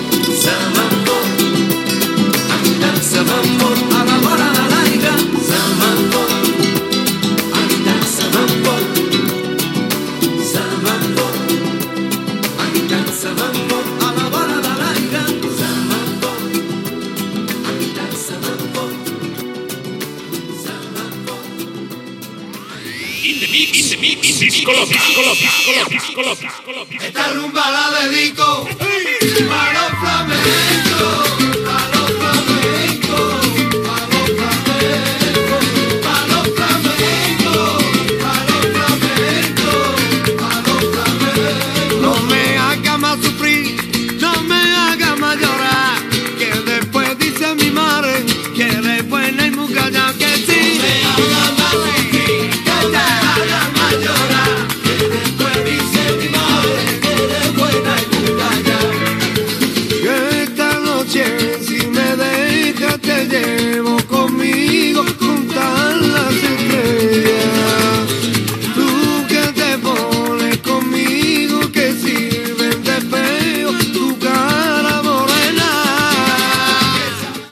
Fórmula musical de rumbes, sense presentador
Musical
FM